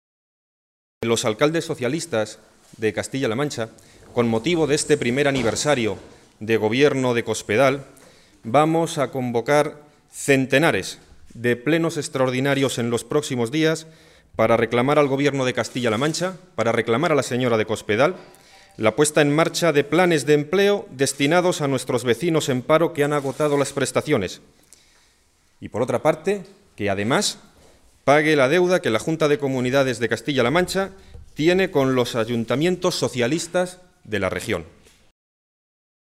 Alcaldes socialistas de las cinco provincias de Castilla-La Mancha comparecieron en rueda de prensa para informar sobre diferentes iniciativas políticas que se llevarán a cabo en los ayuntamientos de nuestra comunidad autónoma
Ha ejercido de portavoz Santiago García-Aranda, alcalde del municipio toledano de Villacañas.